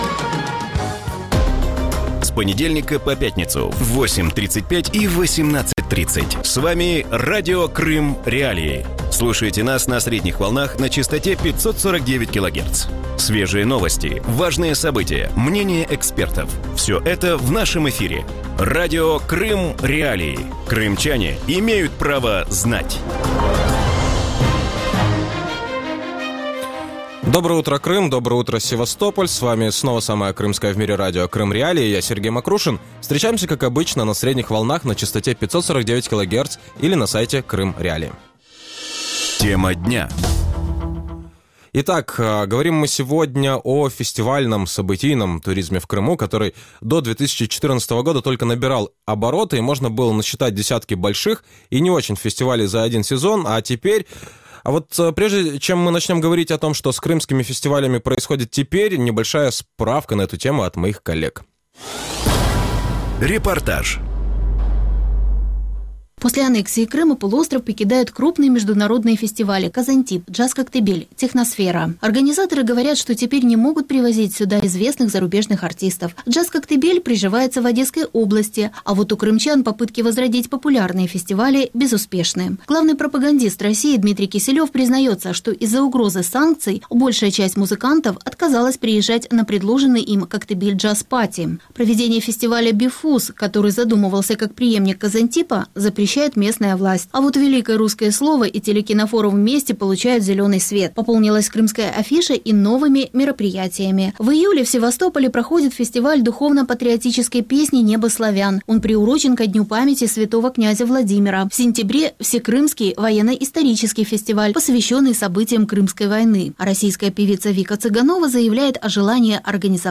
Вранці в ефірі Радіо Крим.Реалії говорять про долю кримського подієвого туризму. Чому, незважаючи на боротьбу за туристів, було закрито «Казантіп»? Якою є доля фестивалів, що були змушені переїхати в континентальну Україну?